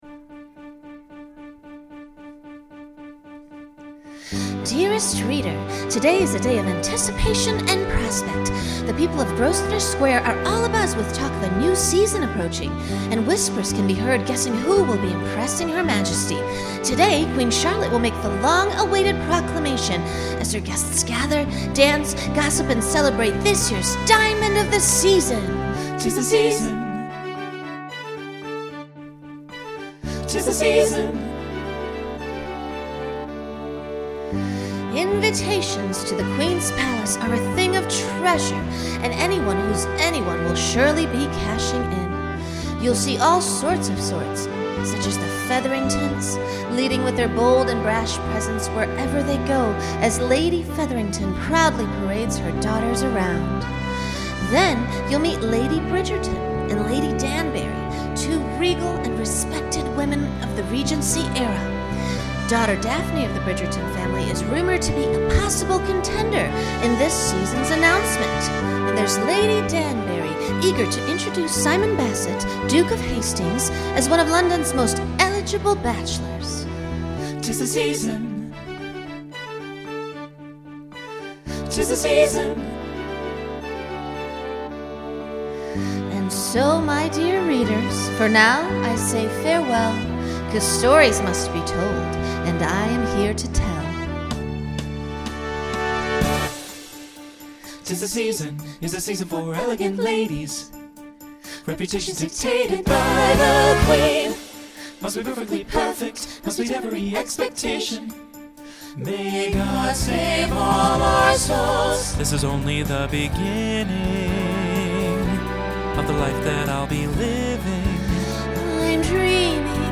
Instrumental combo
Broadway/Film
Story/Theme Voicing SATB